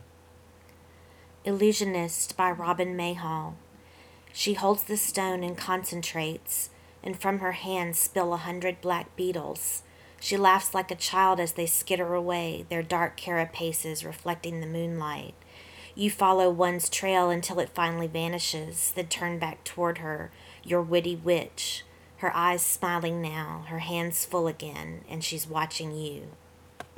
2006 Halloween Poetry Reading